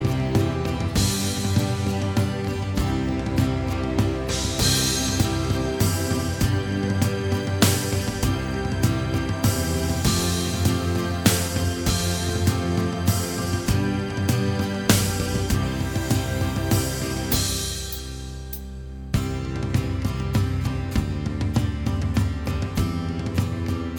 Minus All Guitars Pop (1970s) 3:37 Buy £1.50